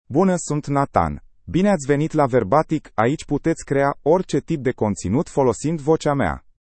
NathanMale Romanian AI voice
Nathan is a male AI voice for Romanian (Romania).
Voice sample
Listen to Nathan's male Romanian voice.
Male
Nathan delivers clear pronunciation with authentic Romania Romanian intonation, making your content sound professionally produced.